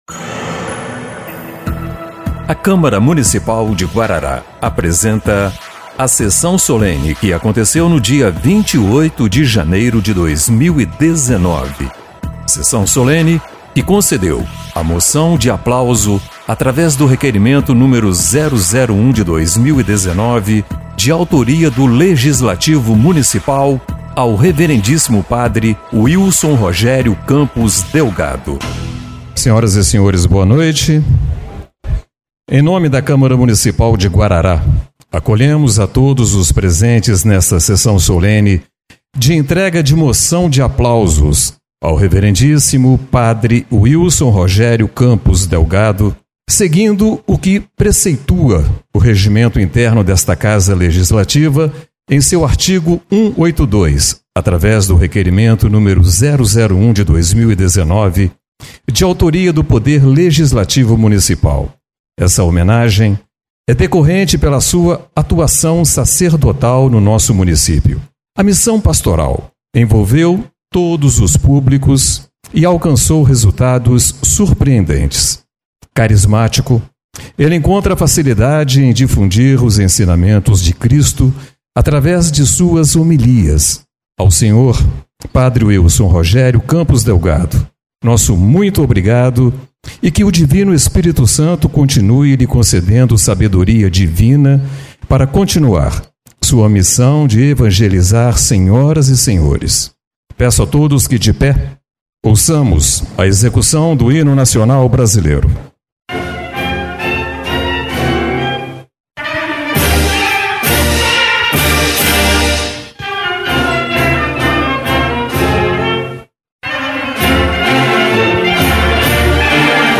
Sessão Solene de 28/01/2019